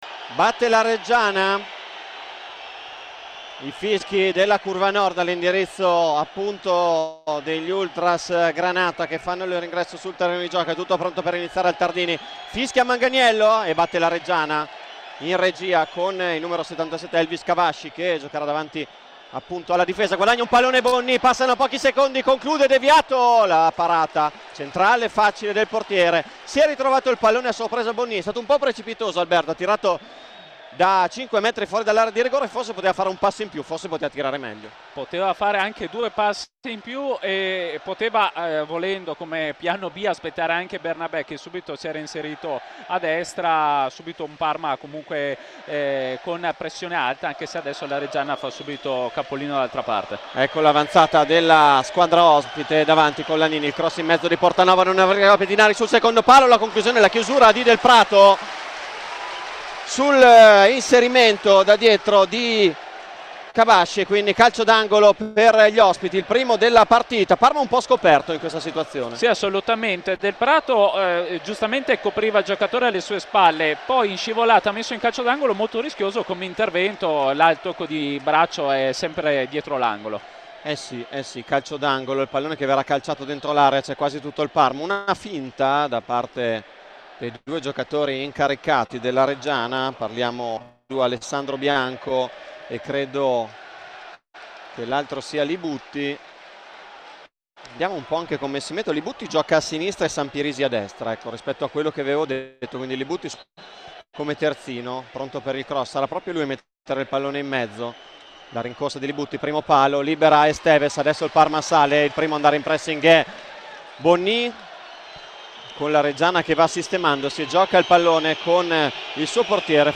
Parma-Reggiana: la sfida più attesa davanti a 16mila spettatori.
Radiocronache Parma Calcio Parma - Reggiana 1° tempo - 2 settembre 2023 Sep 02 2023 | 00:53:19 1x 00:00 / 00:53:19 Subscribe Share RSS Feed Share Link Embed